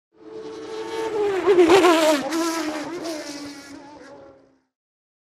Звуки Формулы-1
На этой странице собраны лучшие звуки Формулы-1: от оглушительного рева гибридных двигателей до свиста ветра на прямых.